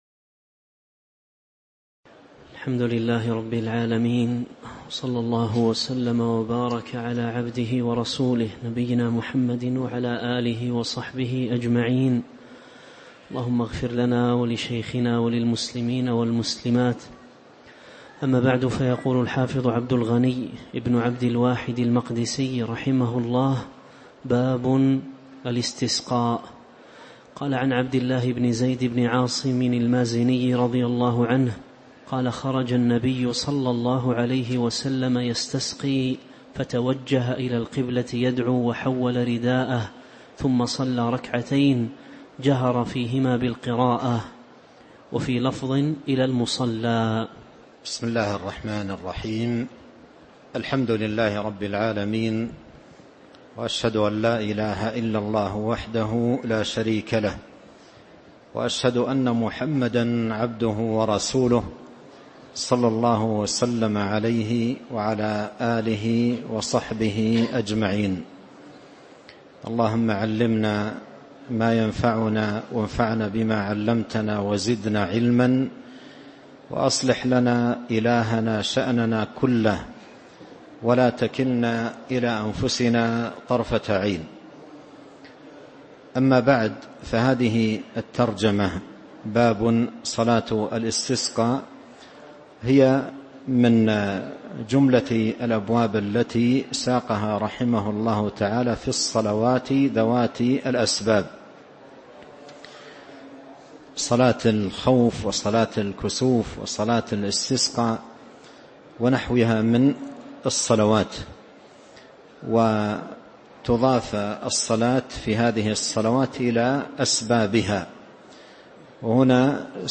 تاريخ النشر ٢٠ جمادى الأولى ١٤٤٤ هـ المكان: المسجد النبوي الشيخ: فضيلة الشيخ عبد الرزاق بن عبد المحسن البدر فضيلة الشيخ عبد الرزاق بن عبد المحسن البدر باب الاستسقاء (024)